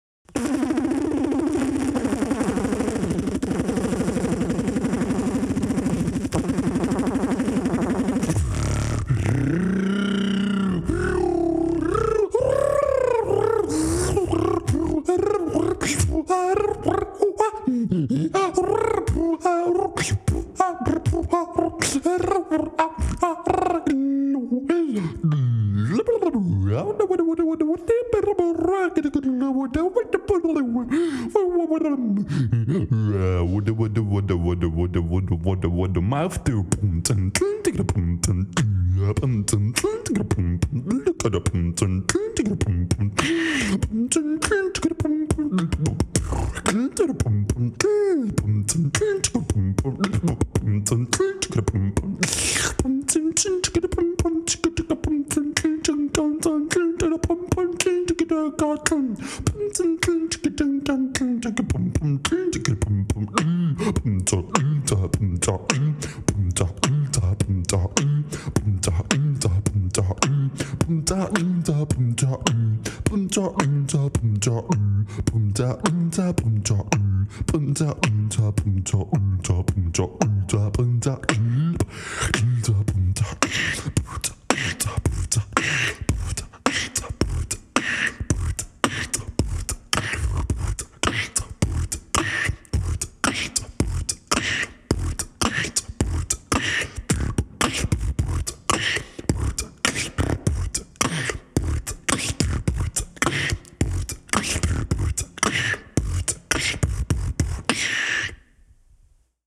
Umím: Voiceover